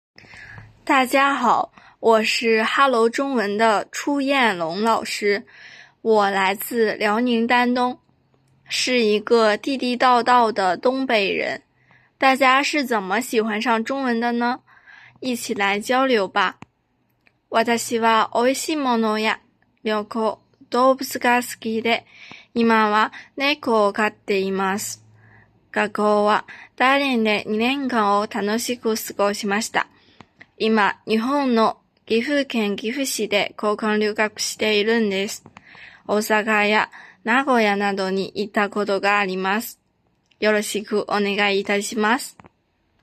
音声の自己紹介